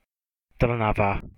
Trnava (Slovak pronunciation: [ˈtr̩naʋa]
Sk-Trnava.ogg.mp3